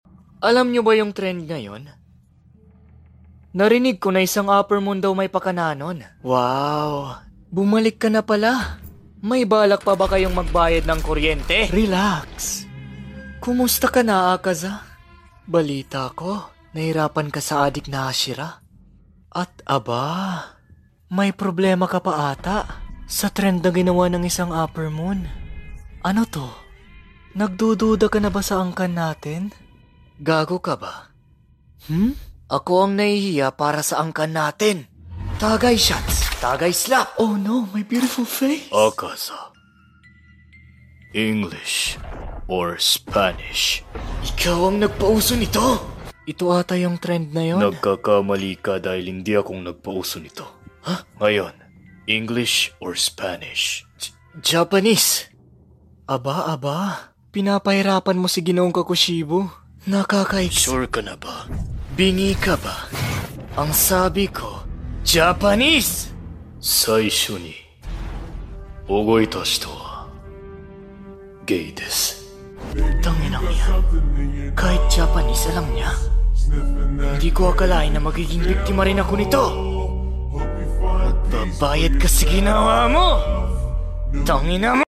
Demon Slayer tagalog parody dub